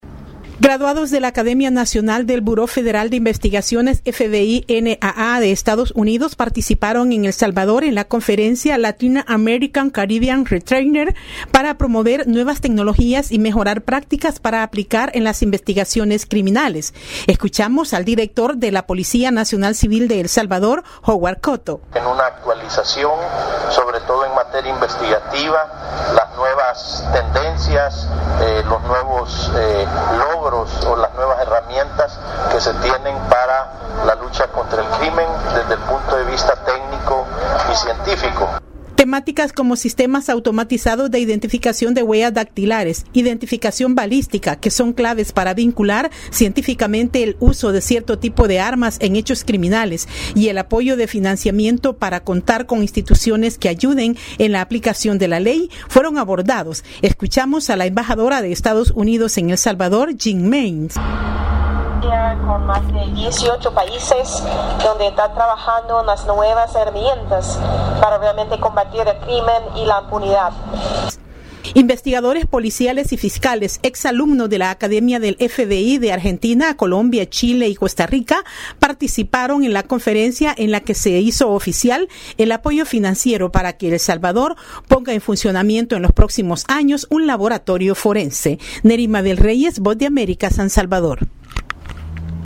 VOA: Informe desde El Salvador